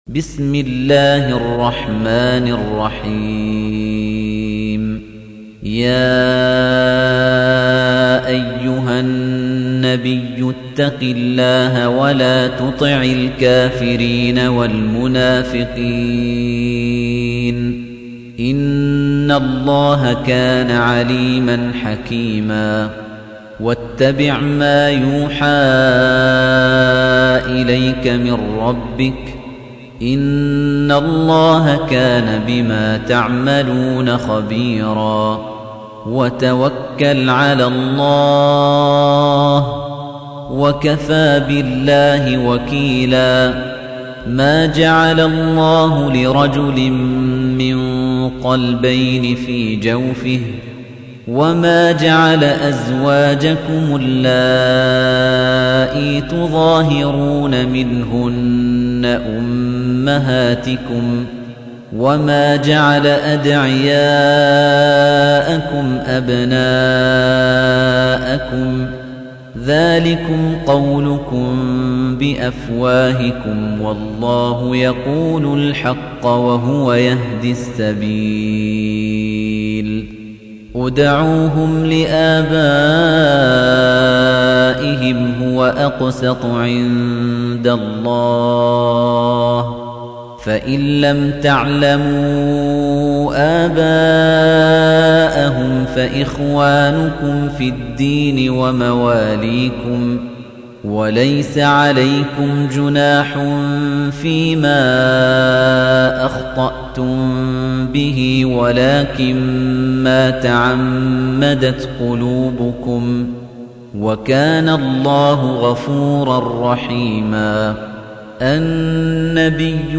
تحميل : 33. سورة الأحزاب / القارئ خليفة الطنيجي / القرآن الكريم / موقع يا حسين